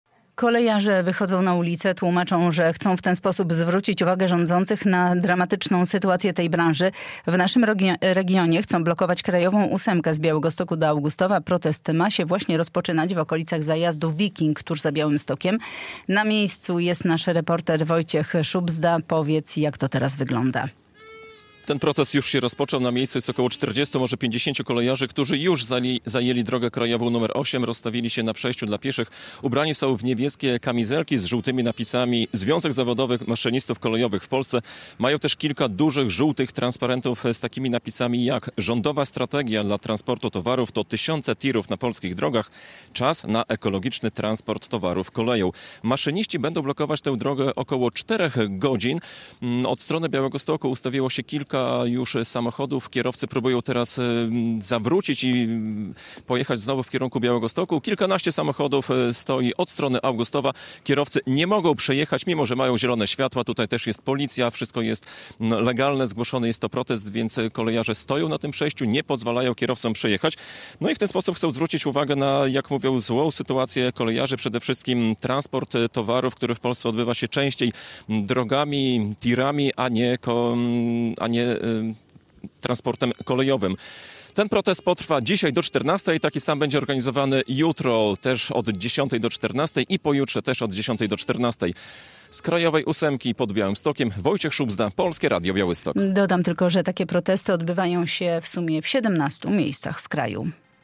Blokada DK nr 8 koło zajazdu Wiking - relacja